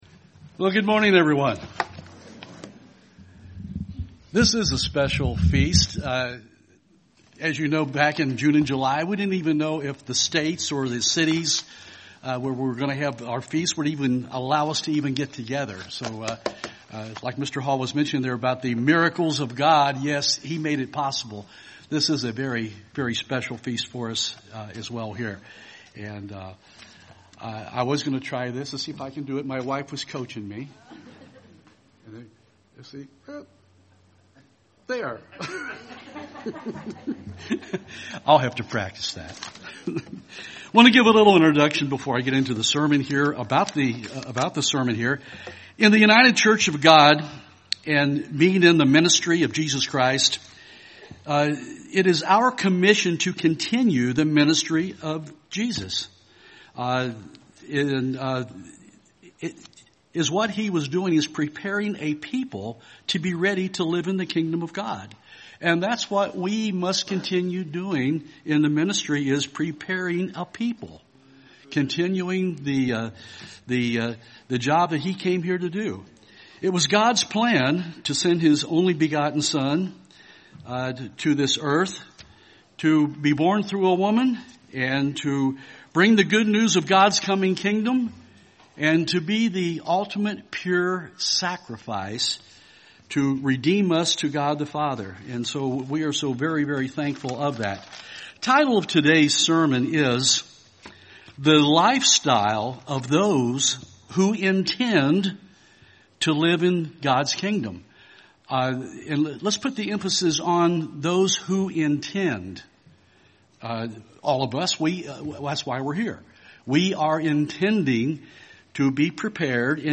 This sermon was given at the Cincinnati, Ohio 2020 Feast site.